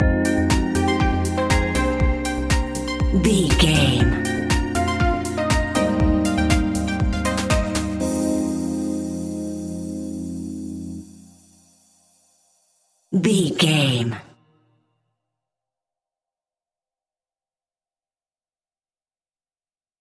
Aeolian/Minor
uplifting
driving
energetic
funky
synthesiser
drum machine
electro house
synth bass